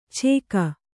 ♪ chēka